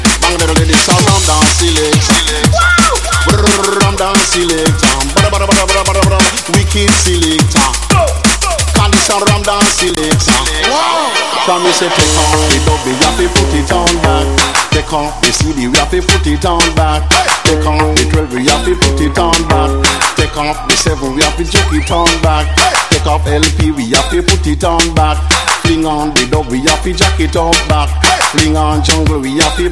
TOP >Vinyl >Drum & Bass / Jungle
TOP > Vocal Track